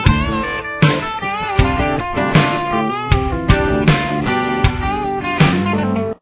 Here is a backing track for practice: